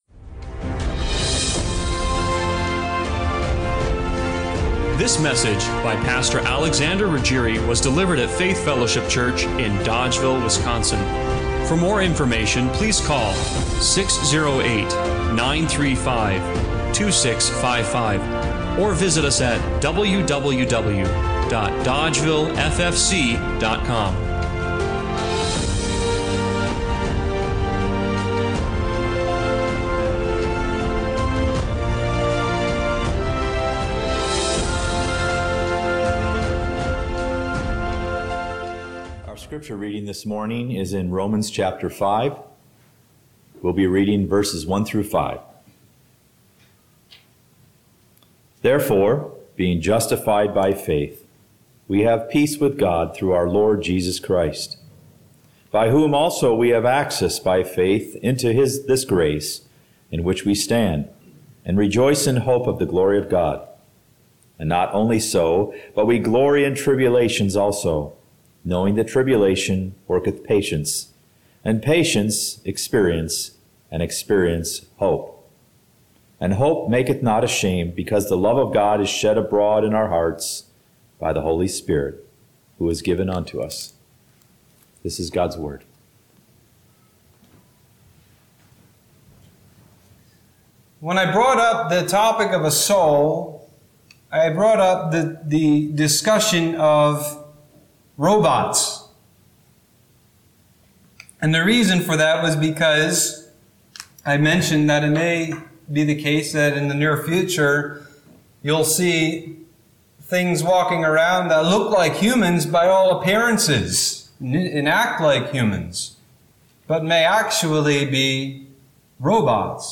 Romans 5:1-5 Service Type: Sunday Morning Worship What makes you you—and not just another version of artificial intelligence?